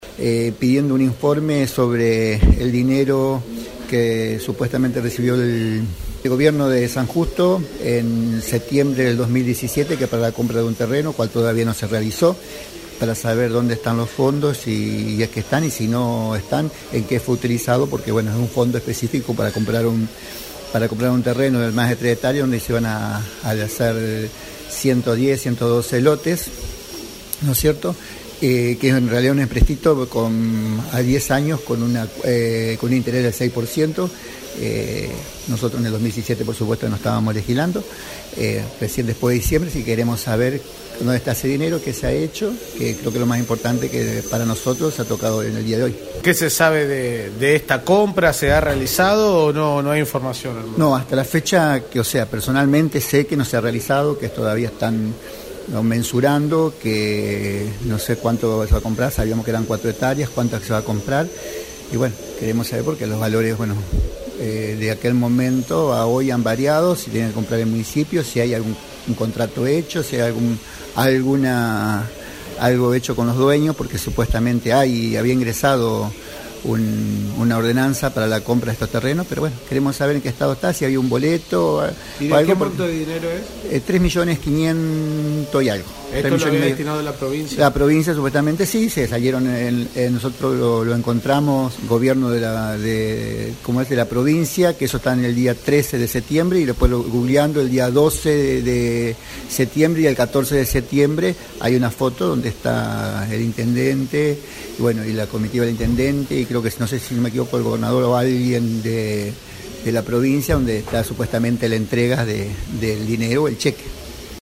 Escuchá a Raúl Florentín explicando el pedido.